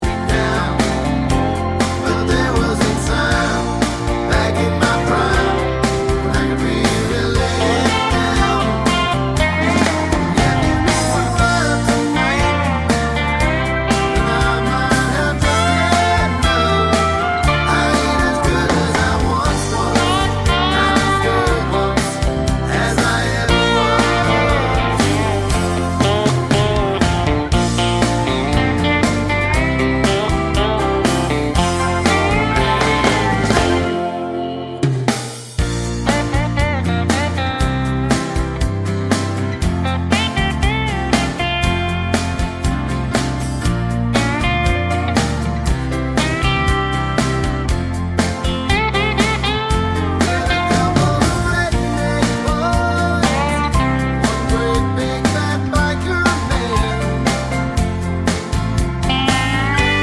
no Backing Vocals Country (Male) 3:45 Buy £1.50